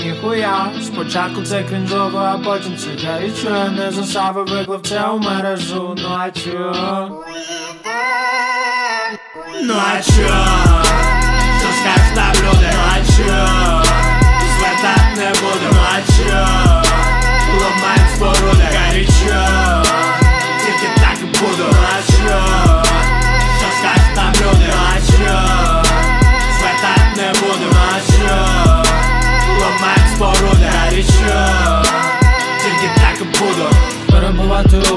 Hip-Hop Rap Rock